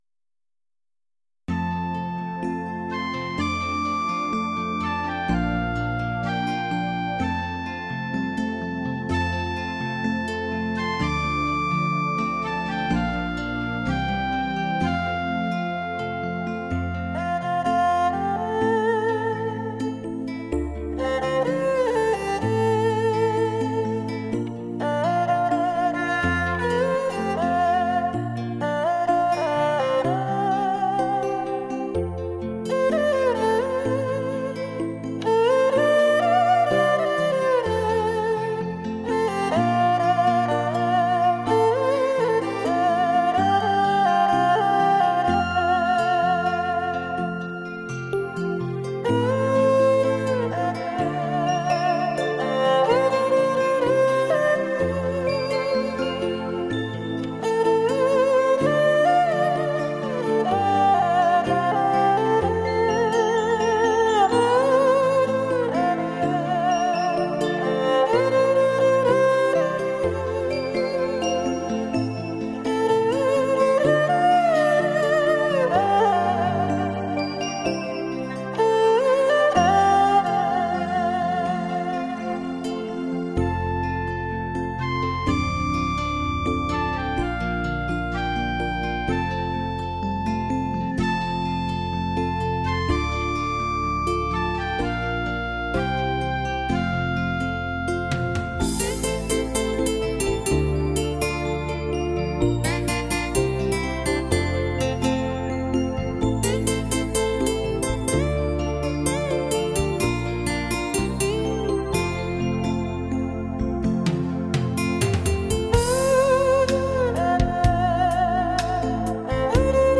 二胡专辑
二胡演奏